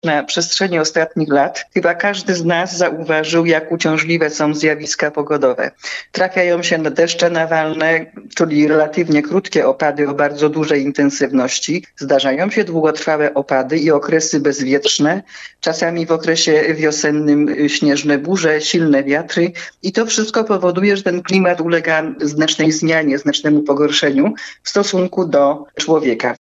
Burmistrz Barlinka Bernarda Lewandowska, w rozmowie z Twoim radiem, wyjaśniła dlaczego magistrat uważa, że taka adaptacja do zmian klimatu jest konieczna?